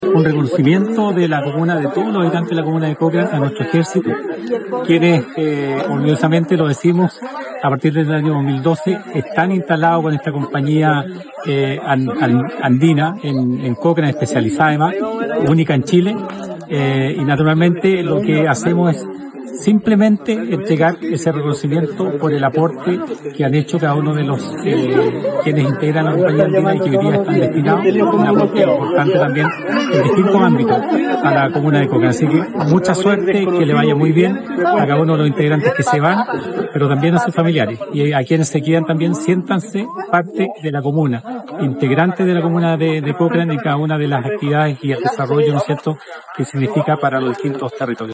Cochrane-. En una solemne ceremonia realizada en la comuna de Cochrane, se entregó la medalla «Ilustre Municipalidad de Cochrane» como reconocimiento al destacado desempeño y compromiso profesional de 23 miembros de la Compañía Andina Divisionaria N.º 20 «Cochrane».
Al cierre de la ceremonia, el alcalde Patricio Ulloa Georgia ofreció unas emotivas palabras, poniendo en valor el trabajo de los homenajeados y su aporte al desarrollo de esta comuna.
Alcalde de Cochrane